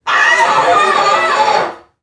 NPC_Creatures_Vocalisations_Robothead [9].wav